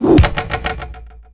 zep_catapult.wav